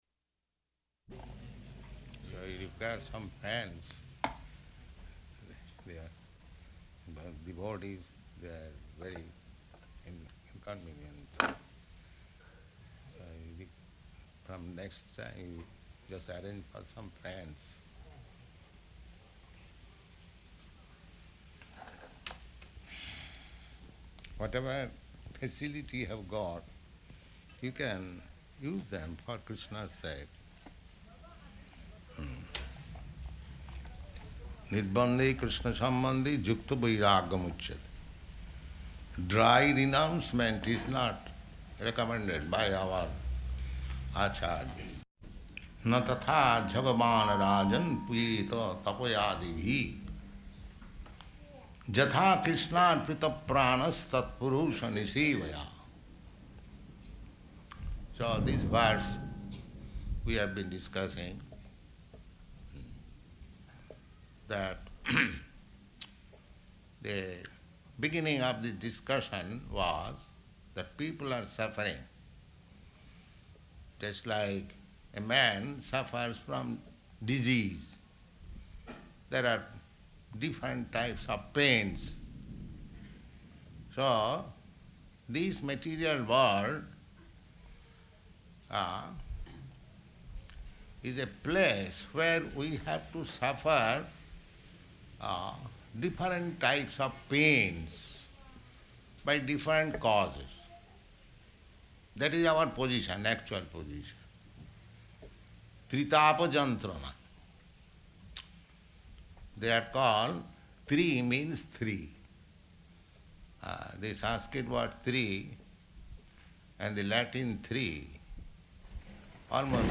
Śrīmad-Bhāgavatam 6.1.16–20 --:-- --:-- Type: Srimad-Bhagavatam Dated: August 2nd 1971 Location: New York Audio file: 710802SB-NEW_YORK.mp3 Prabhupāda: [aside] So you require some fans.
[loud thunder] Now here, this thunderbolt is another pain.